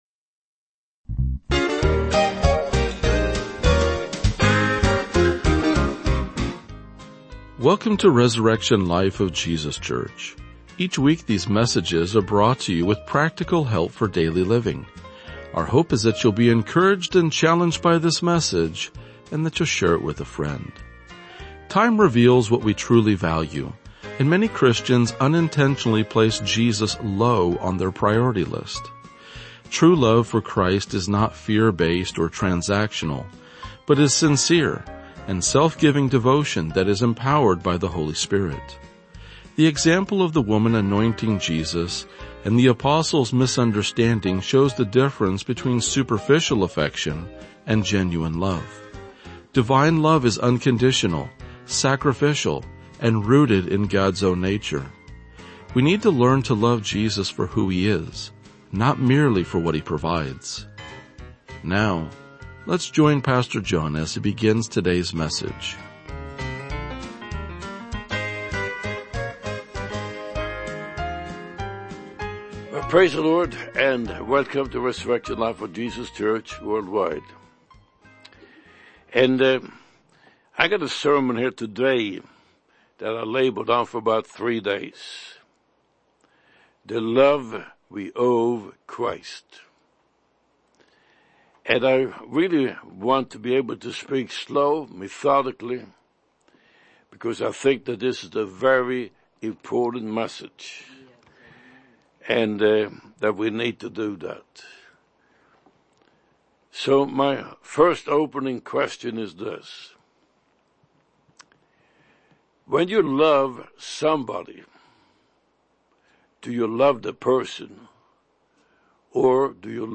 RLJ-2043-Sermon.mp3